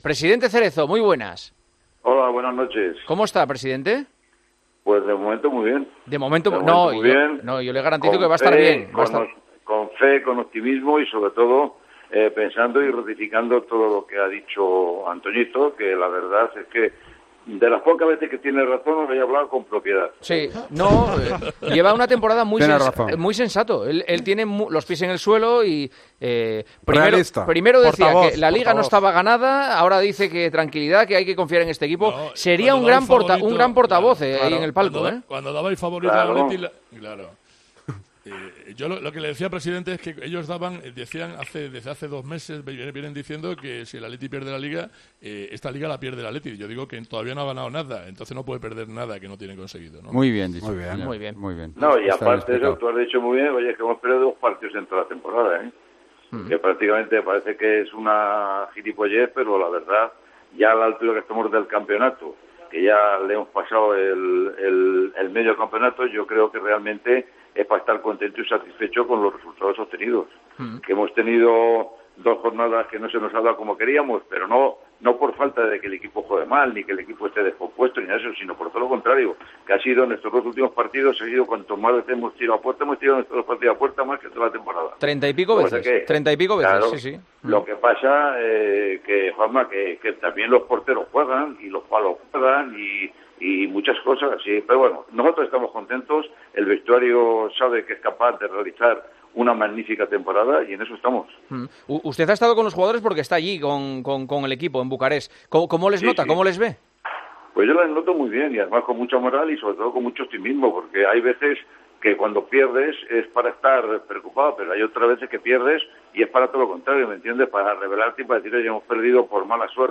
El presidente del Atlético de Madrid, Enrique Cerezo, pasó por los micrófonos de El Partidazo de COPE en la previa del encuentro ante el Chelsea, correspondiente a la ida de octavos de final de la Champions League.